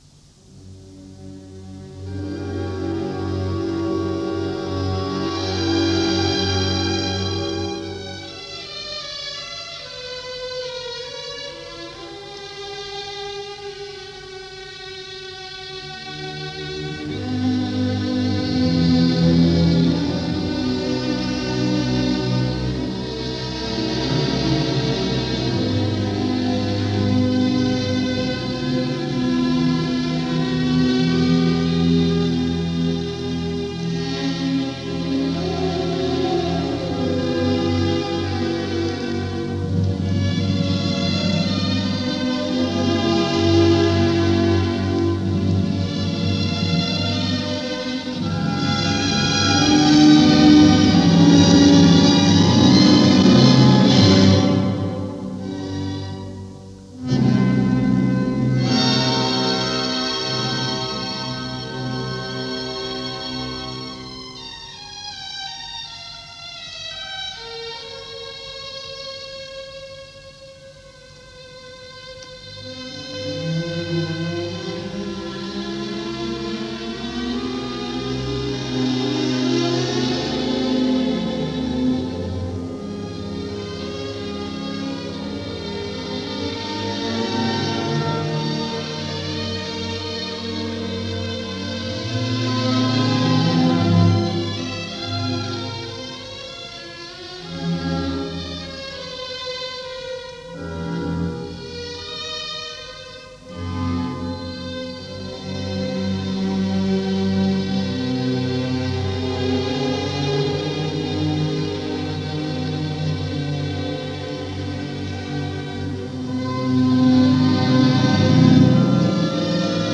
Schumann: Genoveva Overture – Hans Pfitzner Conducts The Munich Radio Symphony – Broadcast 1942
This performance was broadcast several years ago, as the announcement at the end indicates.
So, a rare performance by a legendary figure, recorded during a time of war and preserved by a method we would take for granted less than 10 years later.